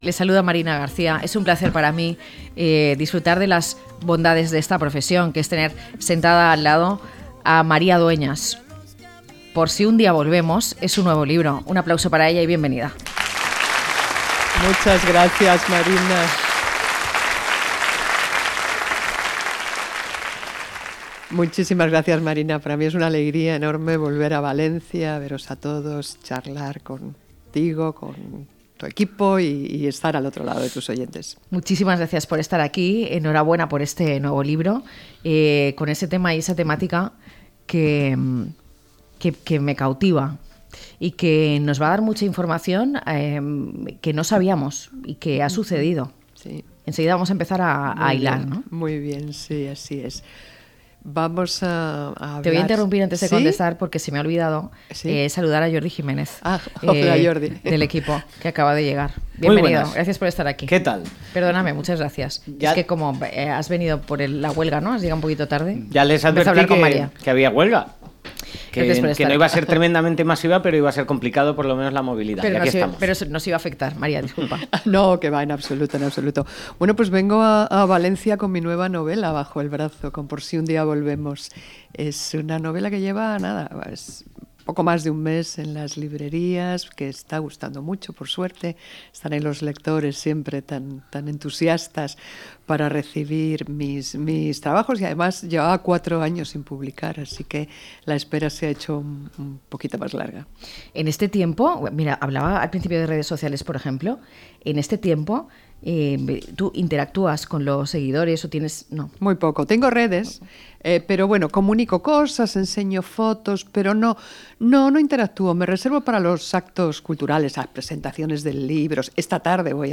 María Dueñas presenta Por sí un día volvemos Recibimos a la escritora María Dueñas, la aclamada autora de El Tiempo entre costuras presenta su nueva novela Por sí un día volvemos, la historia de una una joven que llega a Argelia con el falso nombre de Cecilia Belmonte.